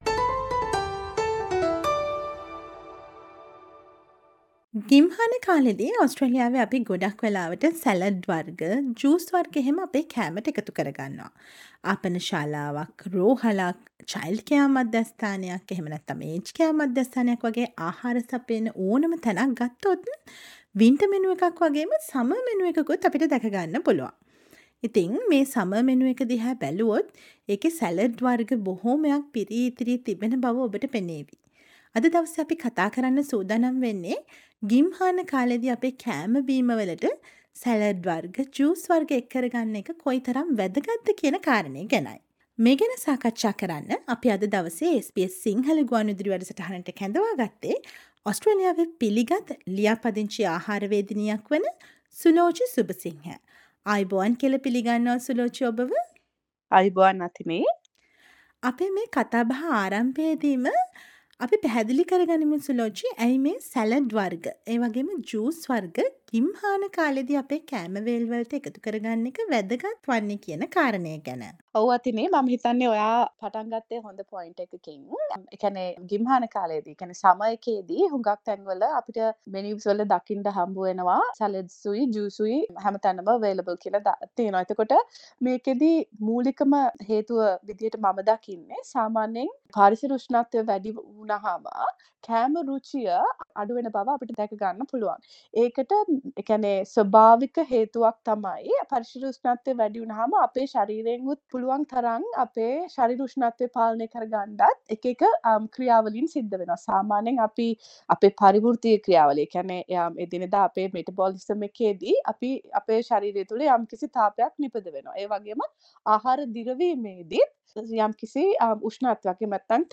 Listen to the latest news from Australia,across the globe, and the latest news from the sports world on SBS Sinhala radio news – Monday, 26 December 2022.